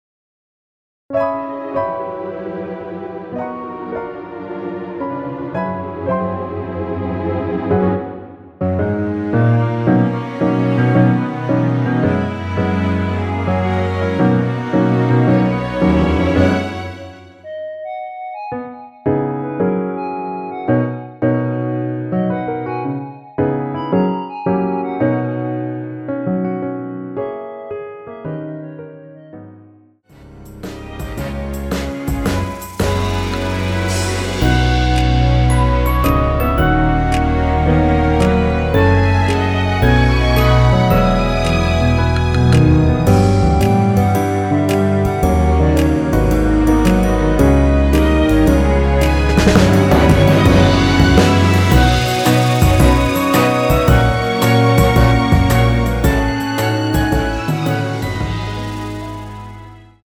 원키에서(-3)내린 멜로디 포함된 MR입니다.
멜로디 MR이란
앞부분30초, 뒷부분30초씩 편집해서 올려 드리고 있습니다.
중간에 음이 끈어지고 다시 나오는 이유는